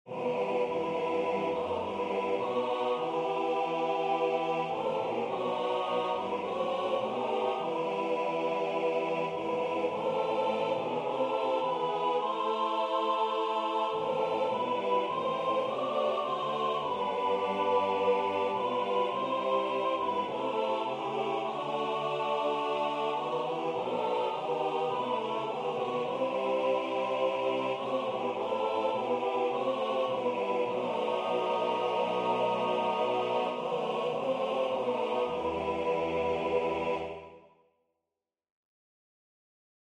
Parais ô Saint Esprit  vient d’un cantique allemand/arabe, sur un air populaire libanais.
Cantique-Parais-o-Saint-Esprit-melodie-4-voix-.mp3